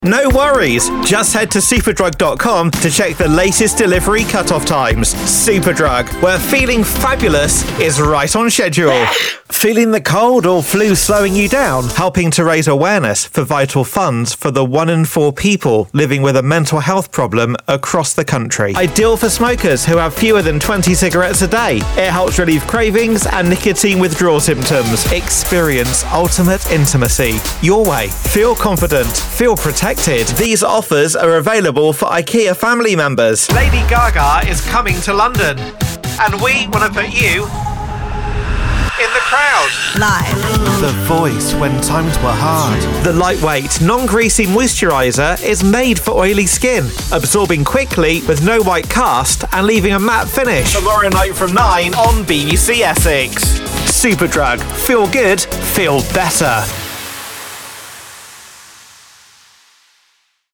I have a versatile style to my voiceover work. It can be powerful and commanding to casual and nonchalant.
English (North American) Adult (30-50) | Older Sound (50+)